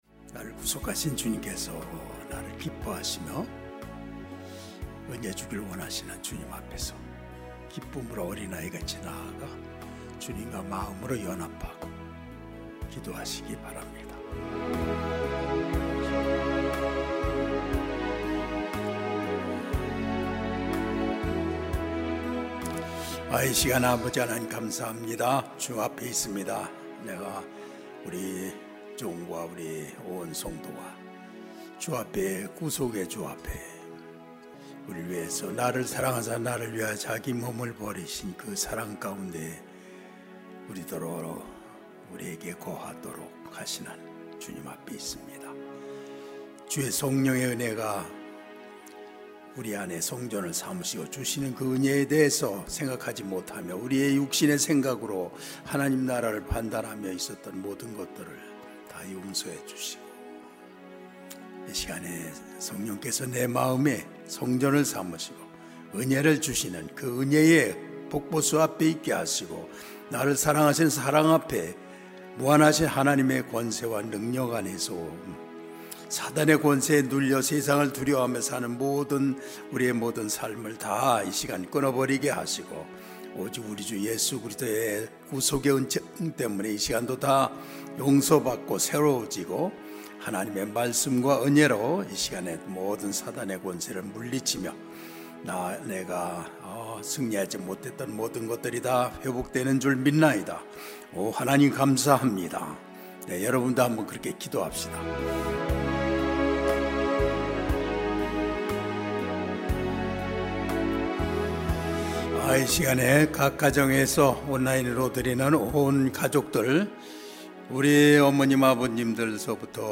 수요가족예배